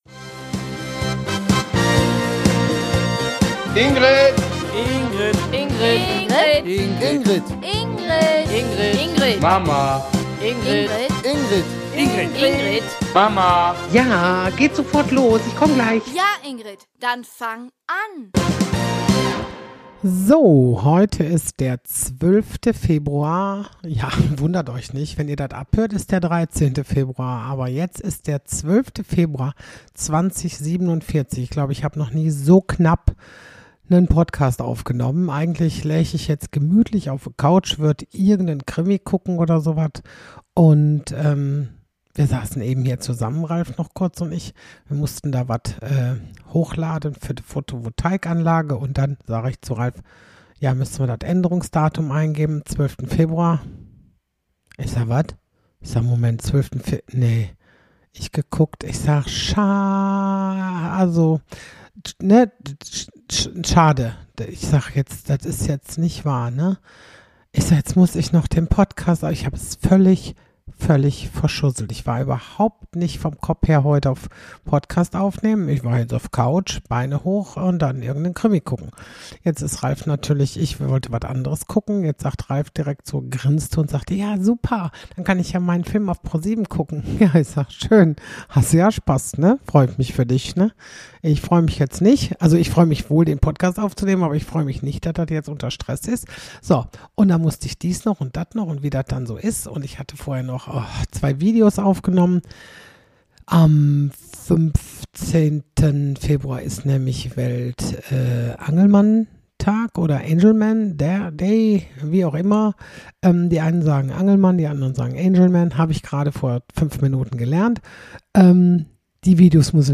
Endlich mal wieder ein Gast in meinem Podcast.
Ich fand es sehr lustig - der Name ist eben Programm, vor allem mit nur einem Mikrofon. Es geht ziemlich drunter und drüber - über Udo Lindenberg, CD-Seife, Nachtfriseurin, Heimweh, Hubschraubern und noch vielem mehr!